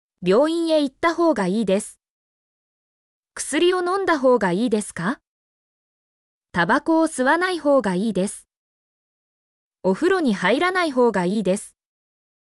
mp3-output-ttsfreedotcom-45_SQOcUsQa.mp3